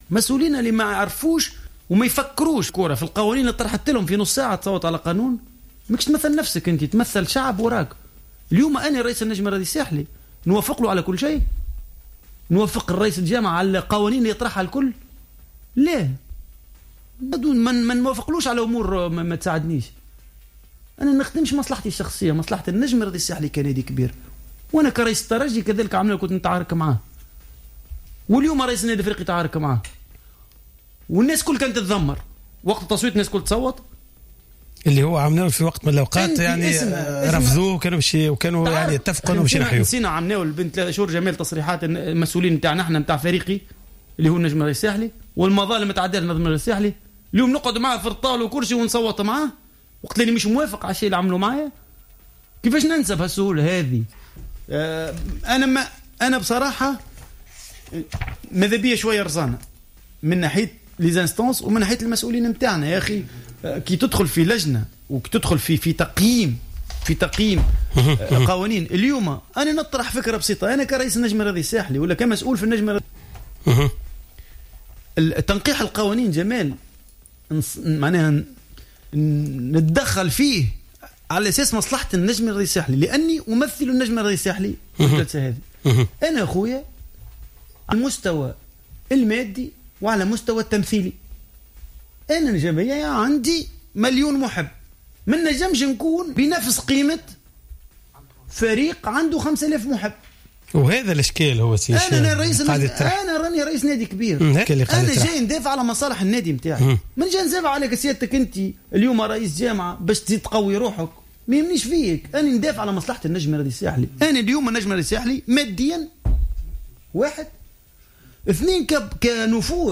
أكد اللاعب الدولي السابق زبير بية خلال إستضافته في برنامج راديو سبور على جوهرة أف أم أنه من غير المعقول أن يبقى مسؤولو الأندية الكبرى مكتوفي الأيدي أمام ما تقوم به جامعة كرة القدم وعليهم أن يفرضوا معاملة خاصة بما أن التصويت خلال الجلسة العامة قد وضع الأندية الكبرى بالتساوي مع أندية من الرابطات السفلى وهي التي تعتبر غير معنية ببعض التنقيحات القانونية التي قد تعود بالضرر أساسا على الأندية الكبرى التي لها إمكانيات مادية و مصاريف و جماهير عريضة.